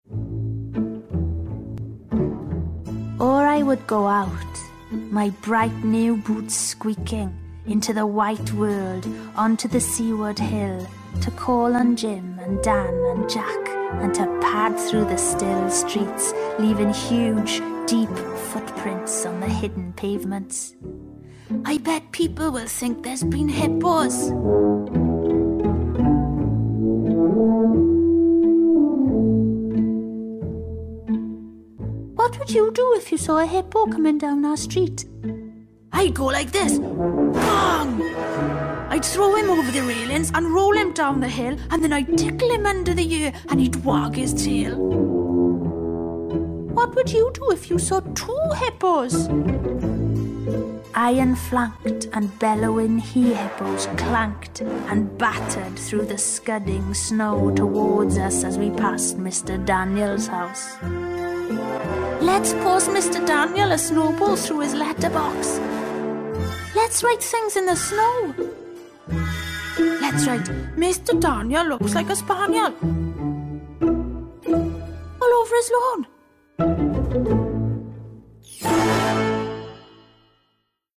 40/50's Welsh, Lyrical/Emotive/Distinctive